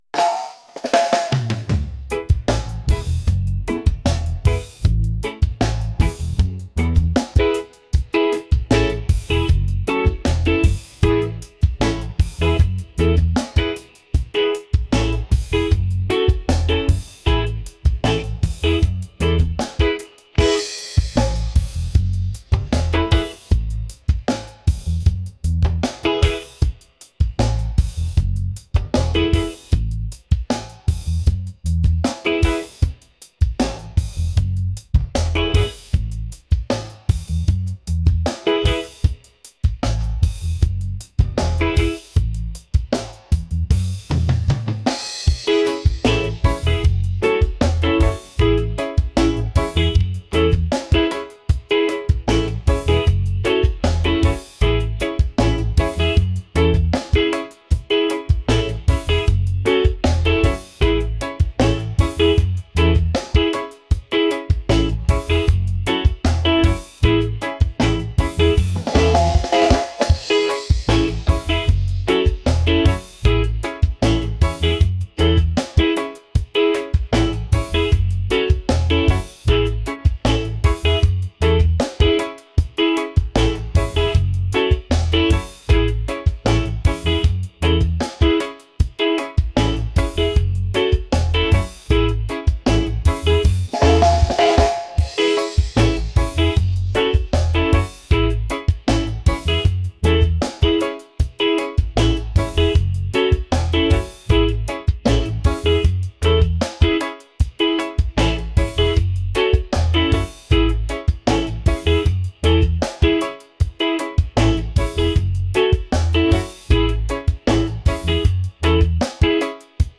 upbeat | romantic | reggae